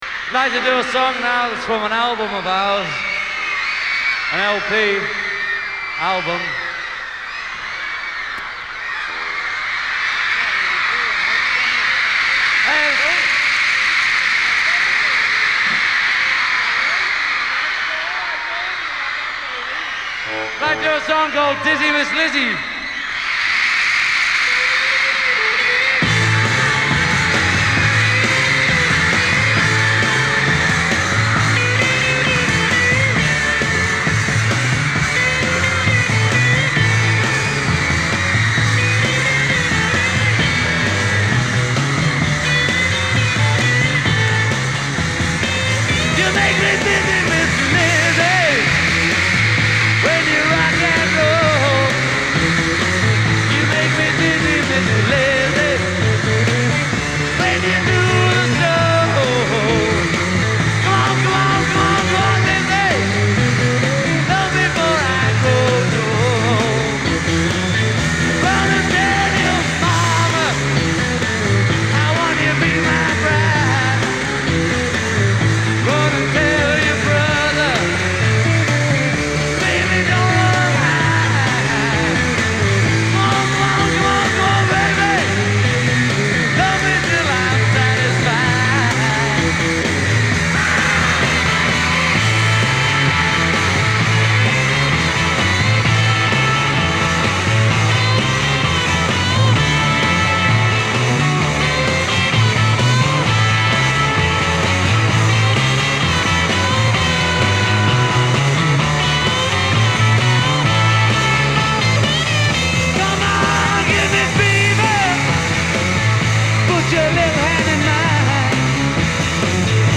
virtually unlistenable
crowd noise